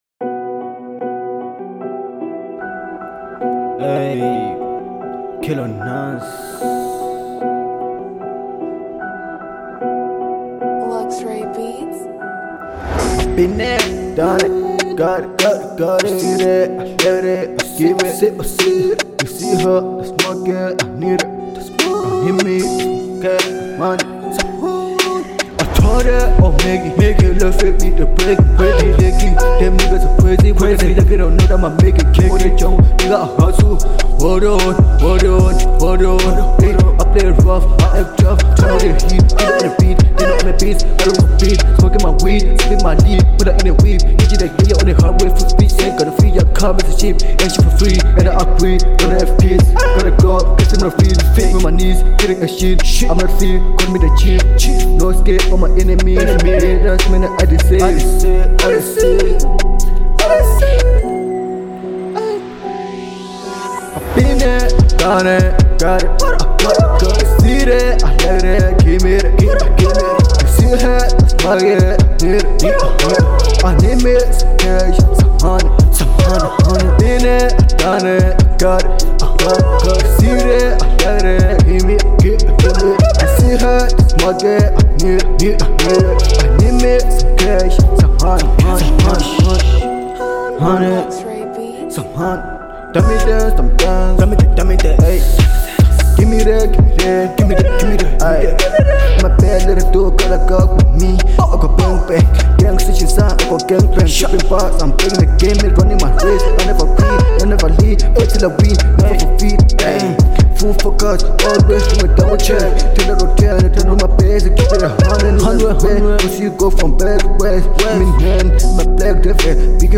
03:25 Genre : Trap Size